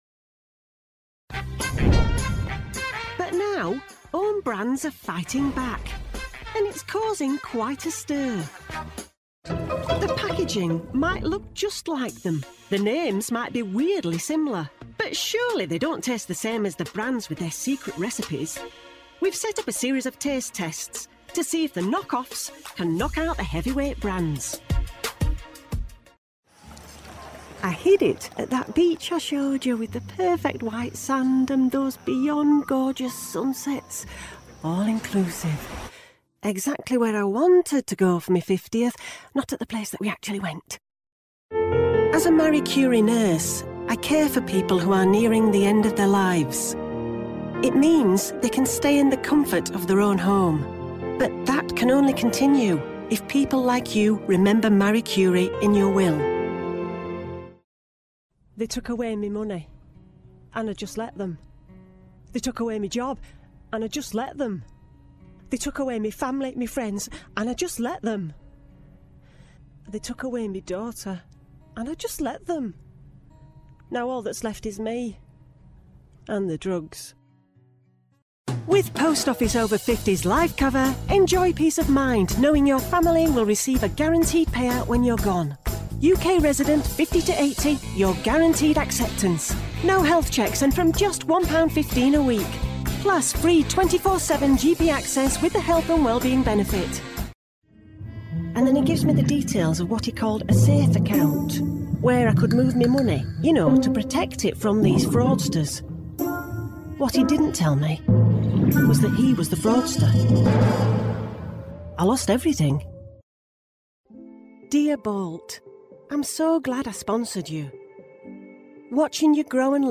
Showreel
Female / 40s, 50s, 60s+ / English / Northern Showreel http